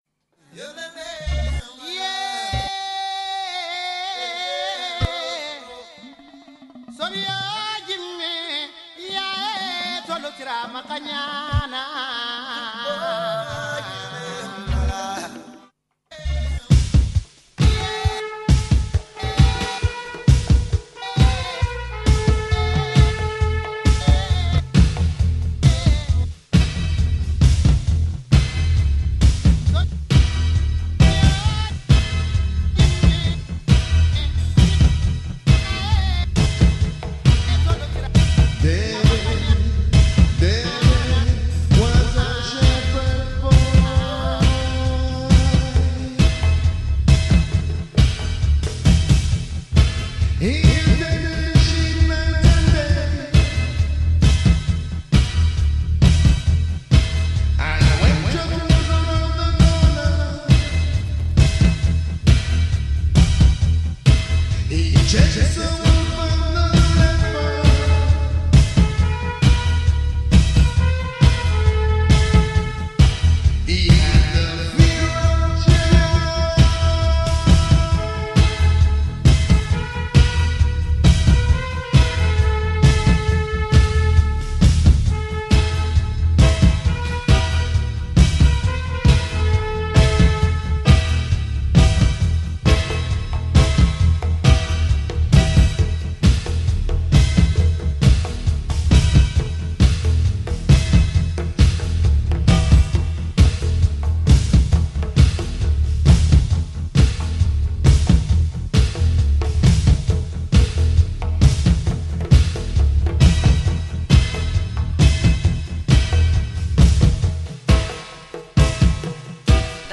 Stream broke - 2 min missed between Pt 1 & Pt 2.